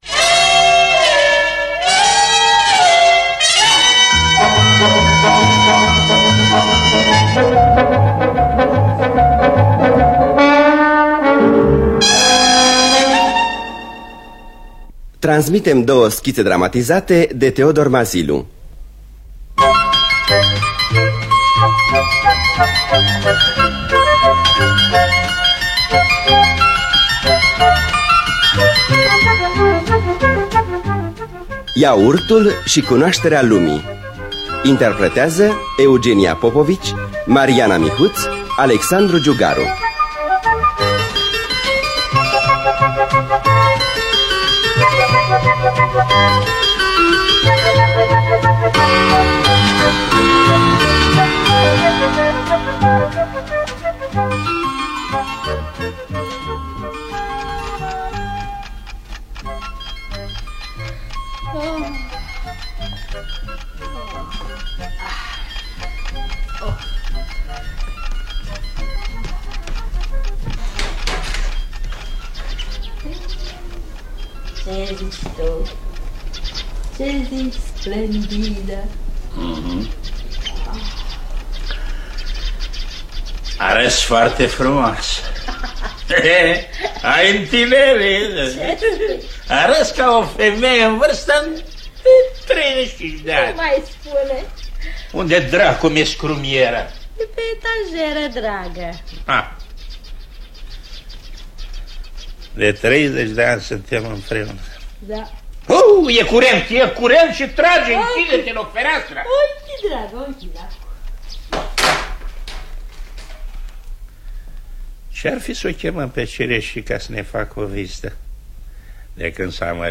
Iaurtul și cunoașterea lumii și Gangsterul de Teodor Mazilu – Teatru Radiofonic Online